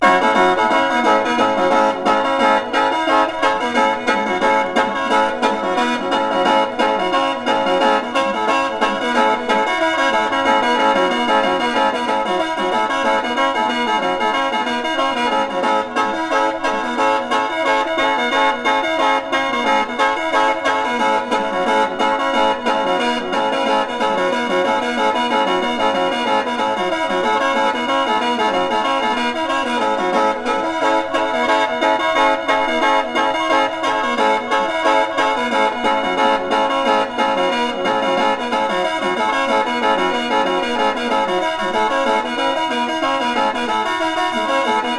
World, Folk, Filed Recording　France　12inchレコード　33rpm　Stereo